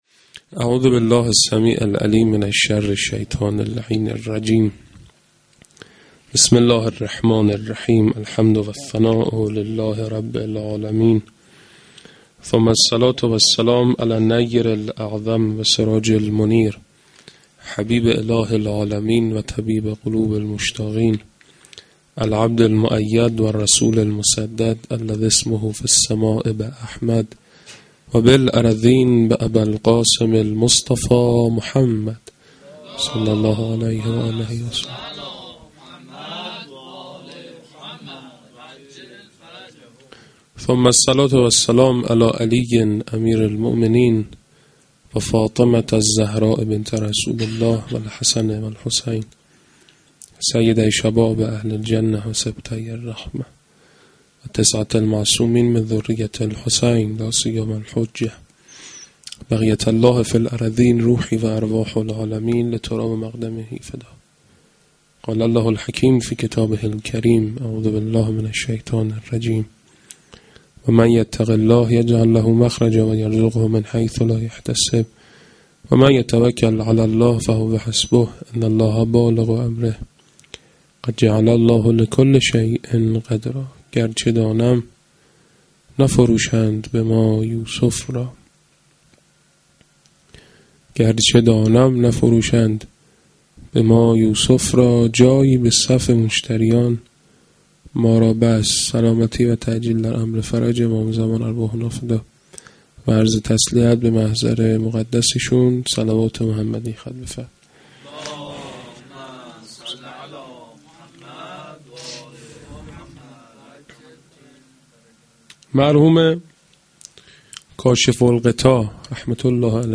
fa-avval-92-sokhanrani.mp3